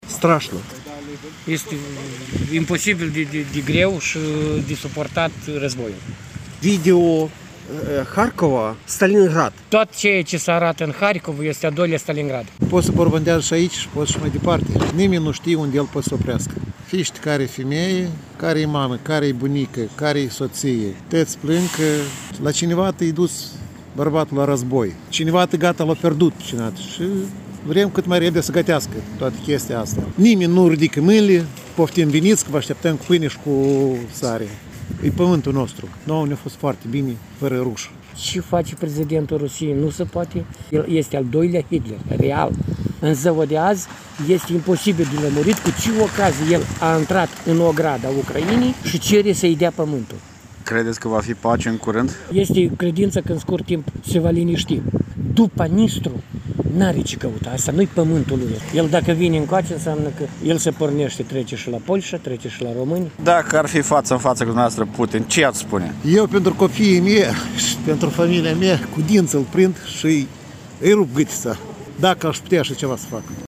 „Strașnic, este imposibil de greu și de suportat războiul”, spune un ucrainean din Cernăuți.